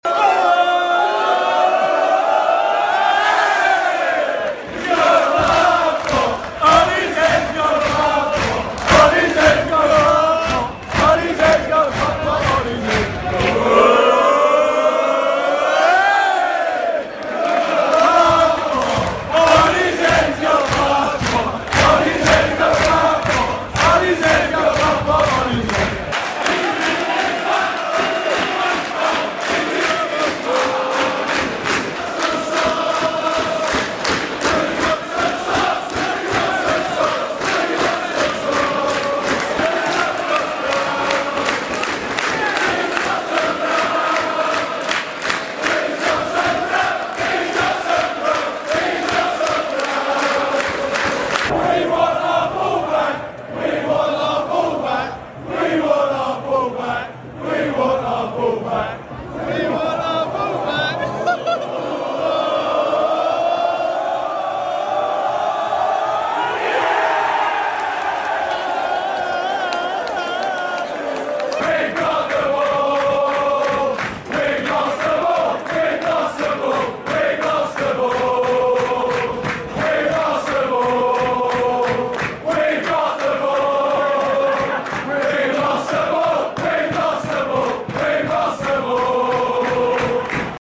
Football fans entertaining themselves